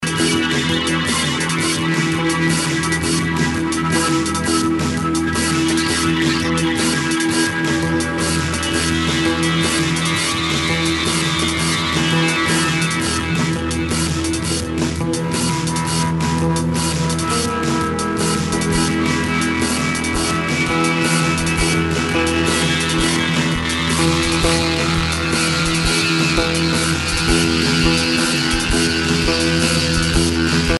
(elektronik, gitarre)